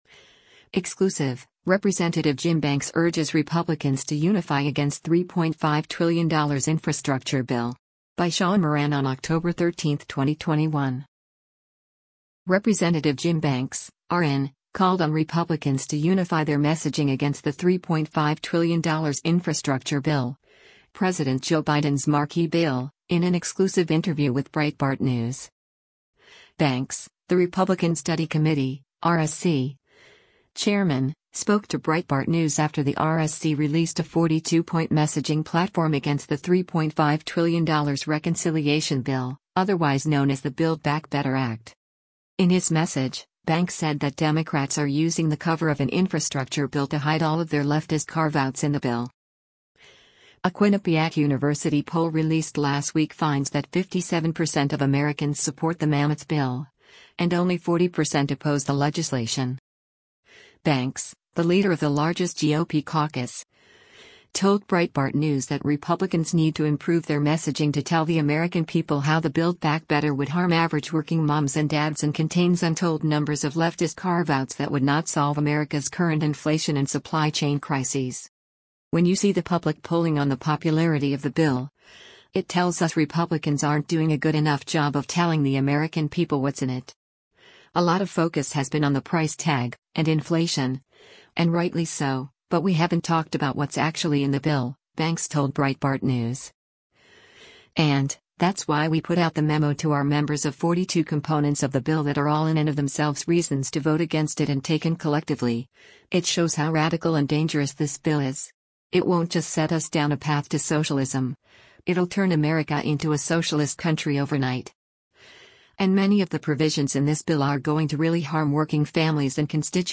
Rep. Jim Banks (R-IN) called on Republicans to unify their messaging against the $3.5 trillion infrastructure bill, President Joe Biden’s marquee bill, in an exclusive interview with Breitbart News.